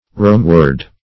Romeward \Rome"ward\, adv.